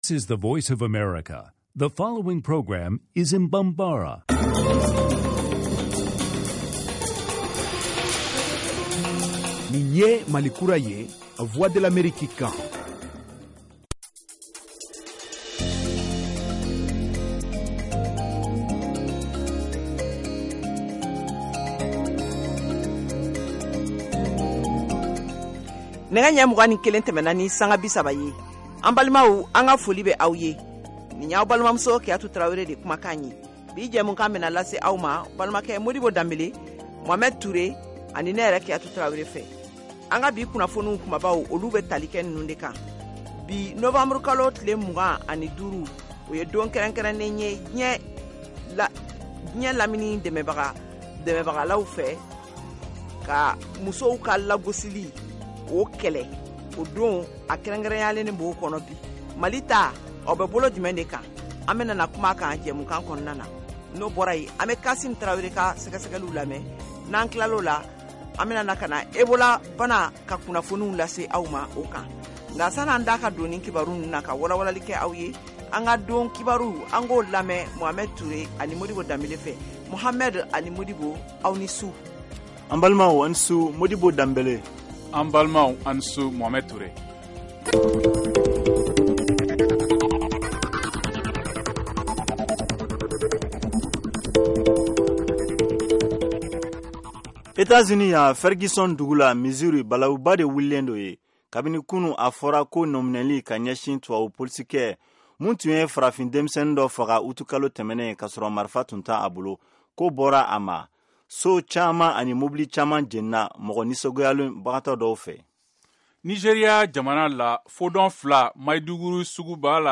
Emission quotidienne en langue bambara
en direct de Washington, DC, aux USA